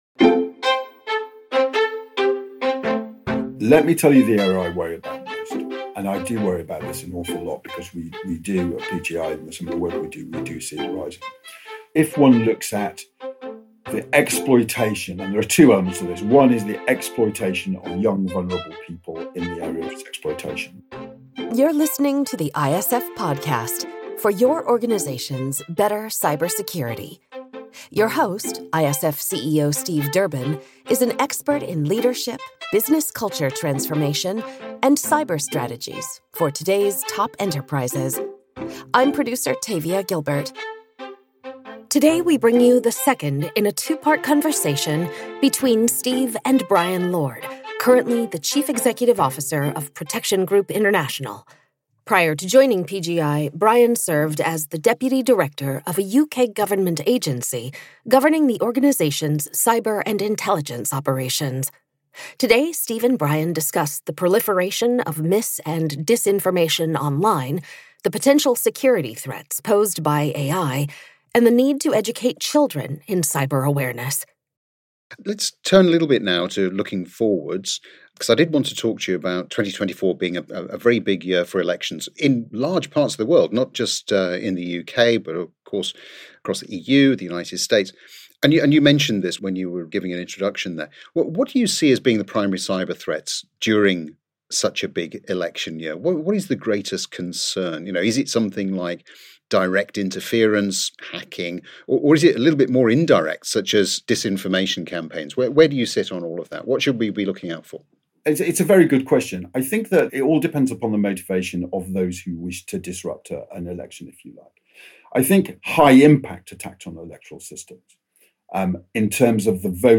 The ISF Podcast brings you cutting-edge conversation, tailored to CISOs, CTOs, CROs, and other global security pros.